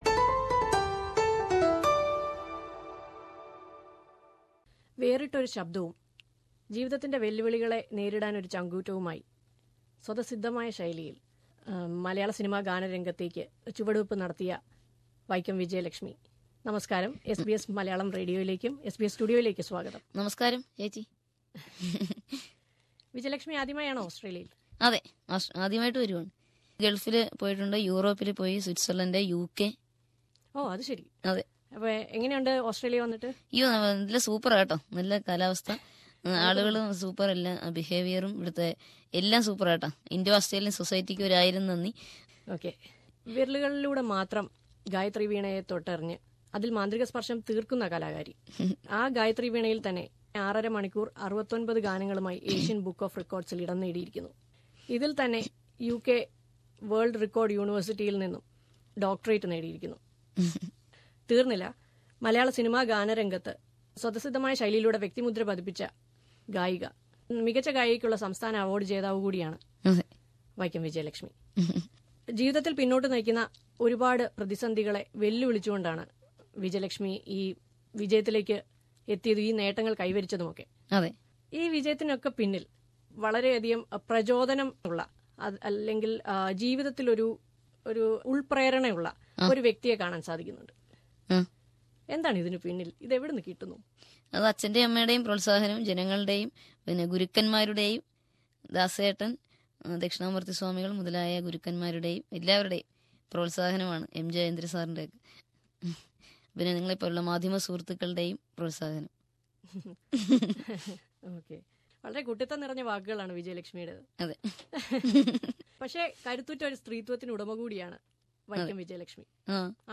Interview with Vaikom Vijayalakshmi
SBS Malayalam Radio talks to the gifted singer Vaikom Vijayalakshmi...